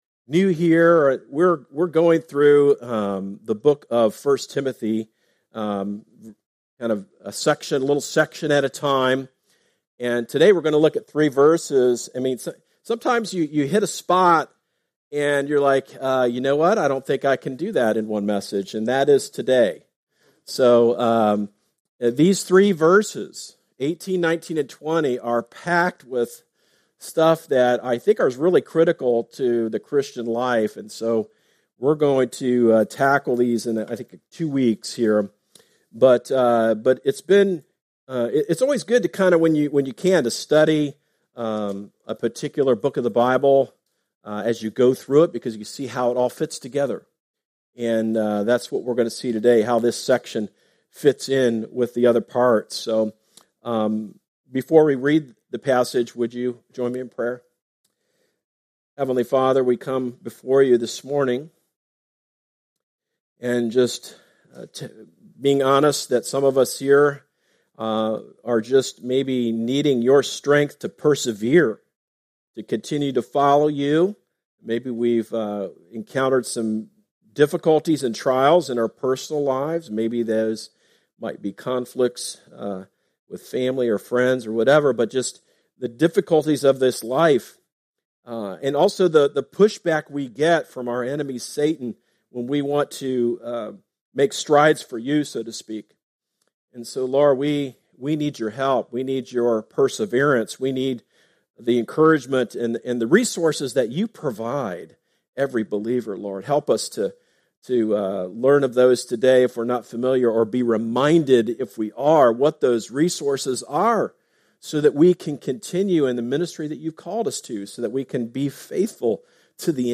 Sermons – Darby Creek Church – Galloway, OH